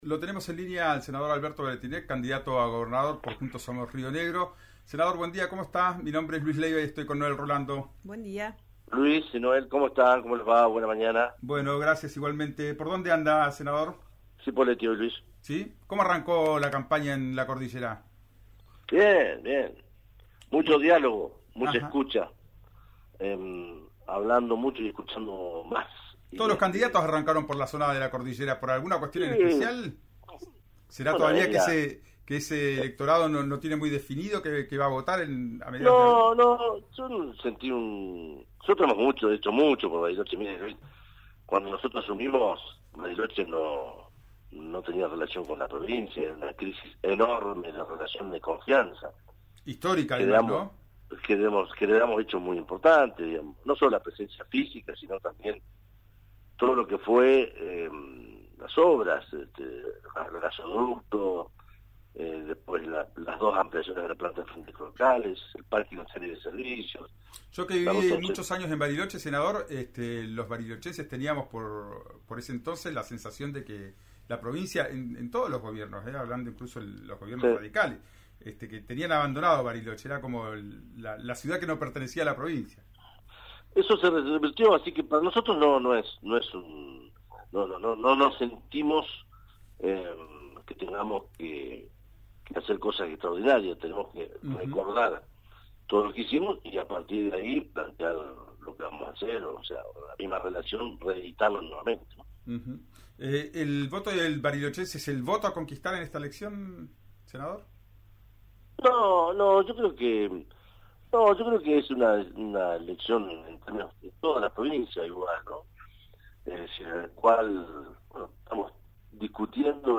Mientras crecen las acusaciones entre funcionarios del Ministerio de Educación y representantes de la Unter, Alberto Weretilneck dialogó con el programa «Ya es Tiempo» de RÍO NEGRO Radio y dejó en claro su postura.